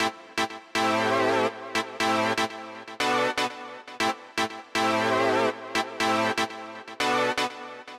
23 ChordSynth PT3.wav